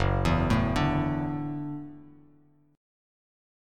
Gbm7#5 chord